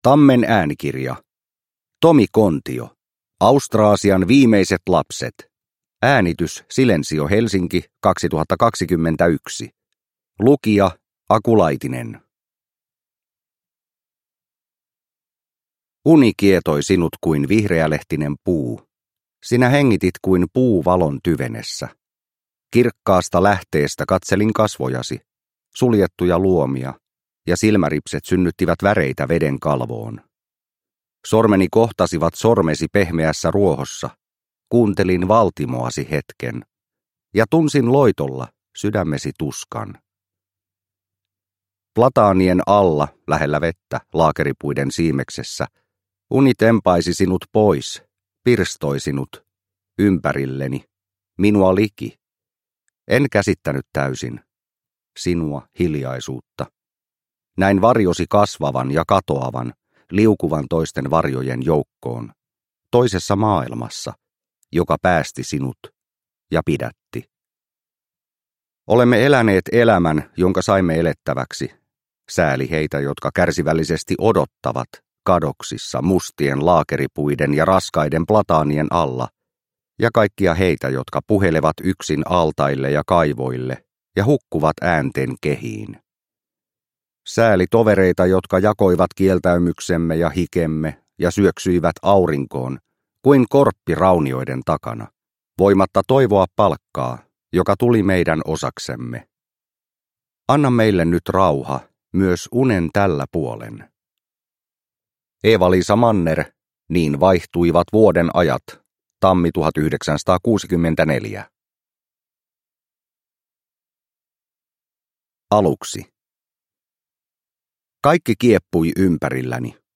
Austraasian viimeiset lapset – Ljudbok – Laddas ner